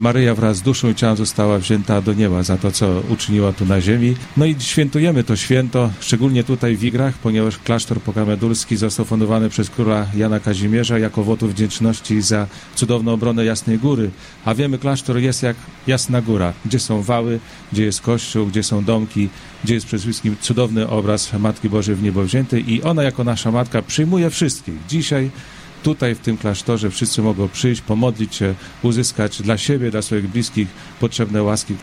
Na miejscu są też nasi reporterzy, którzy na żywo zdają relacje z wydarzenia.